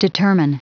Prononciation du mot determine en anglais (fichier audio)
Prononciation du mot : determine